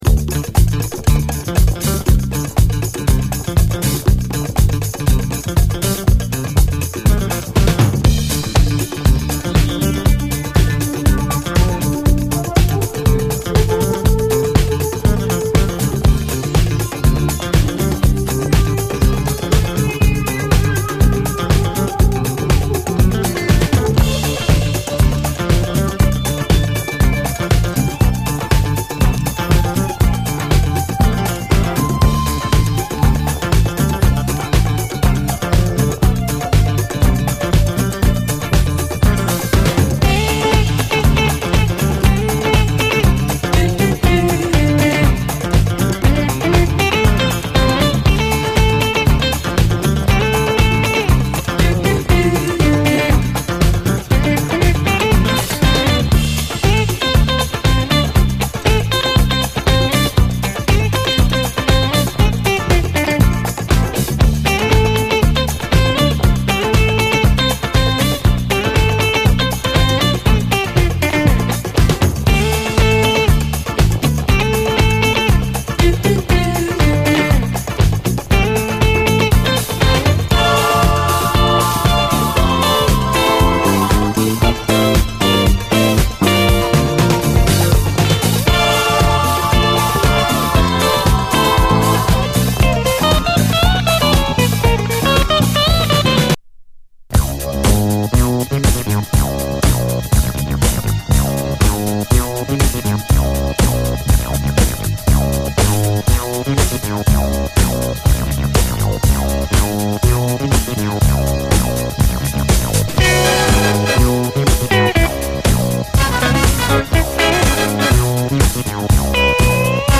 SOUL, 70's～ SOUL, DISCO
LOFTクラシックのシンセが舞うウニョウニョ・ブギー
ギターがガツガツ刻むファンキー・ブギー
いずれもゴージャスかつ華麗に展開する感じがいい！